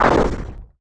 Index of /App/sound/monster/chaos_ghost